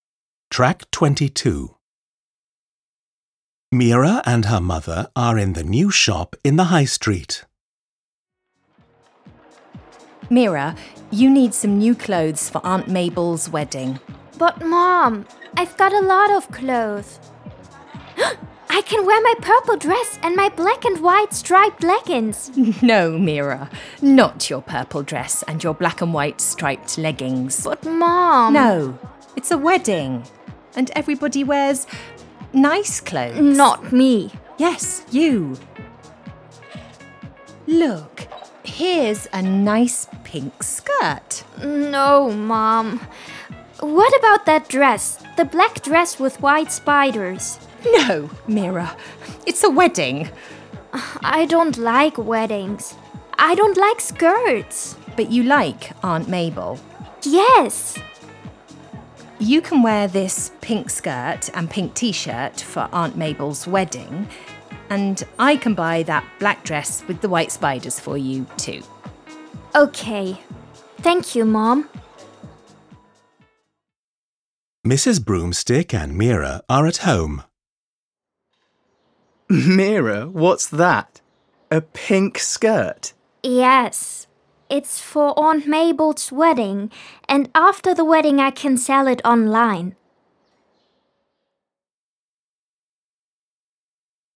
hören den Dialog das erste Mal, um grob den Inhalt erfassen zu können.
Im Falle dieses Dialogs kann man die Klasse in 2 Gruppen einteilen.